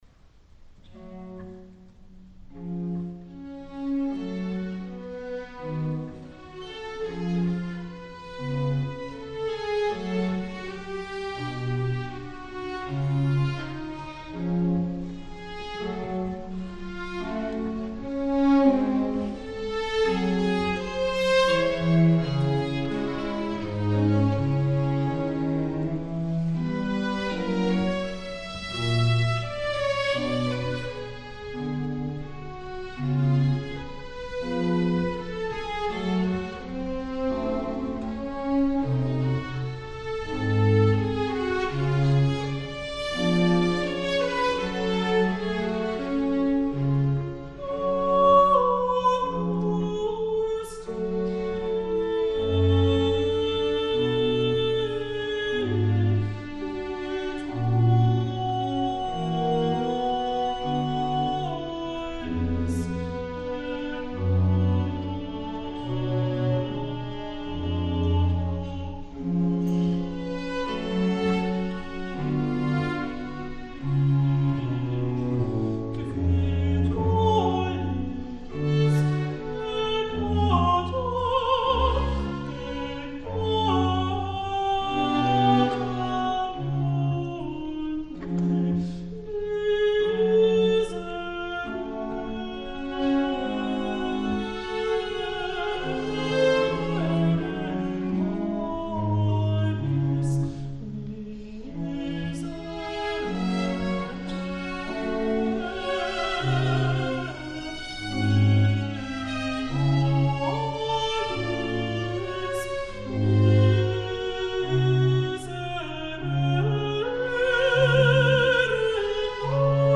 Les Arts Florissants
contratenor